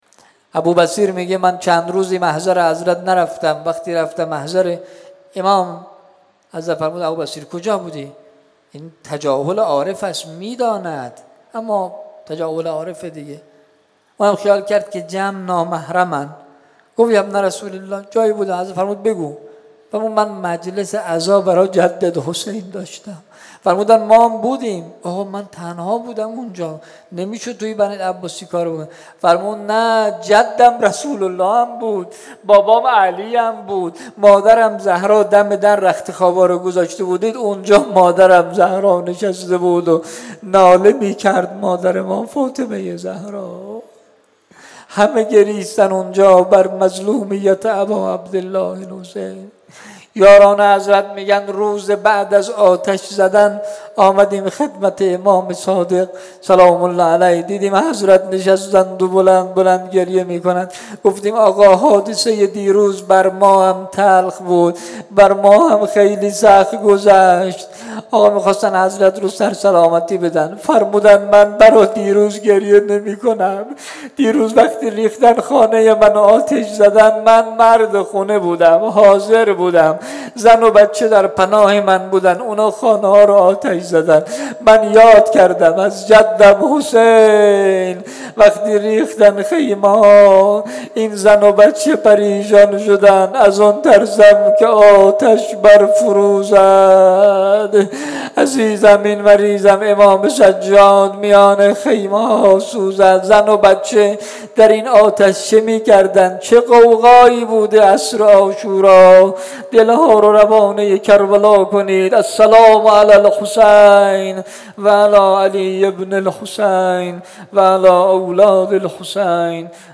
شهادت امام صادق علیه السلام-شب اول